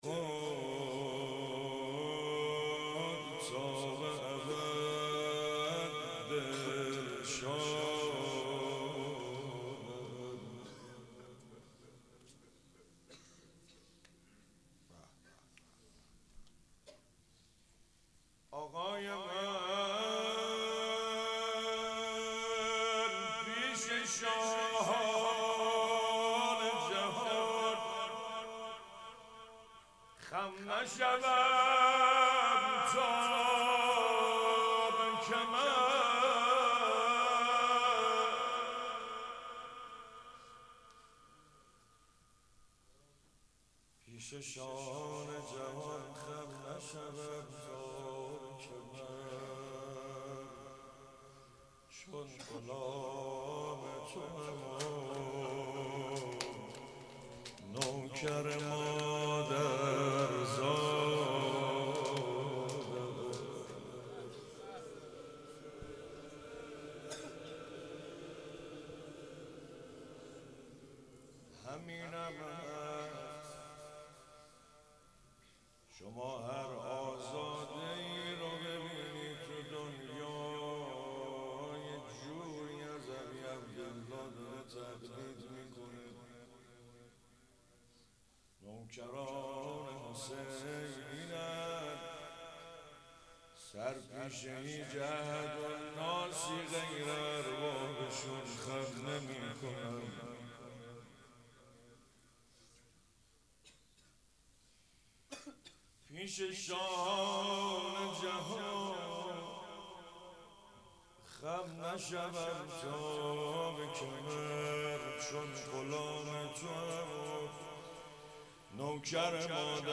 اربعین 97 - روضه